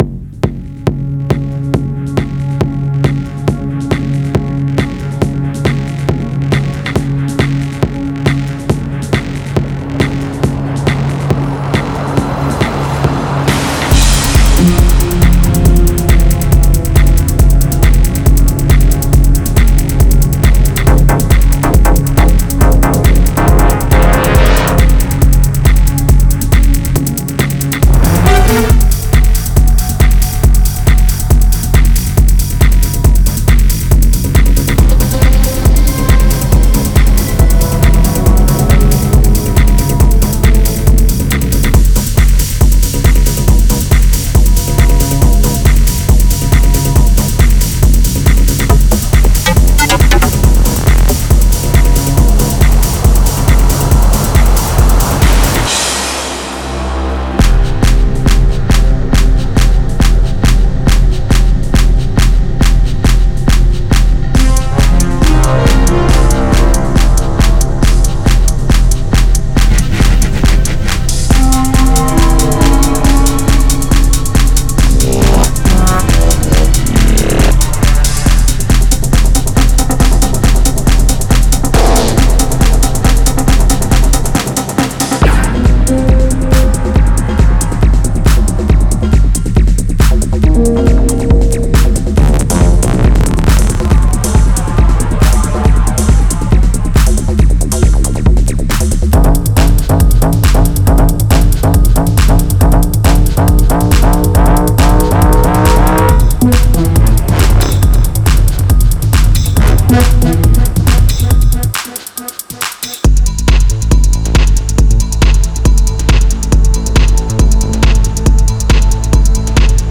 Genre:Techno
ここではダンスフロアの原始的な力とシンフォニーオーケストラの壮大なスケールが激しく衝突します。
高らかに舞い上がるストリングスと灼熱のシンセライン、そしてノコギリ波のように重厚なブラススタブを作り出しました。
地響きを起こす極太テクノキックループ、精密に研ぎ澄まされたトップループ、そして複雑なパーカッションループ。
デモサウンドはコチラ↓